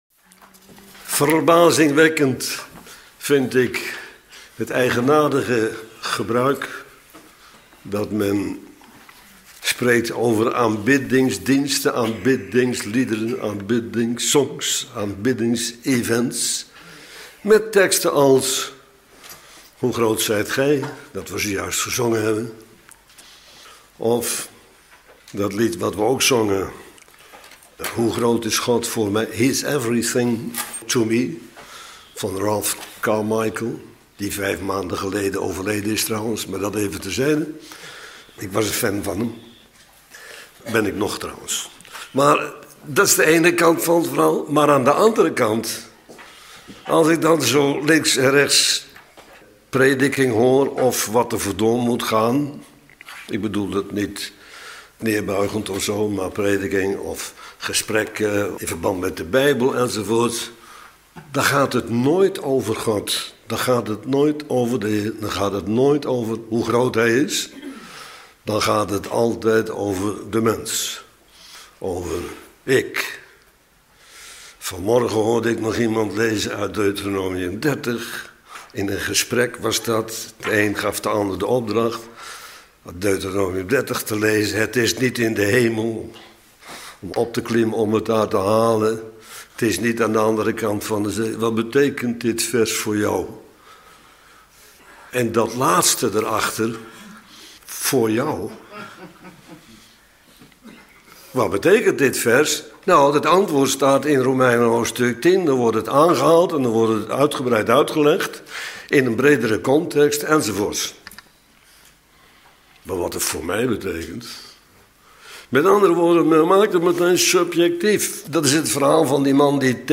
Bijbelstudie lezing over: Is het ook dat God gezegd heeft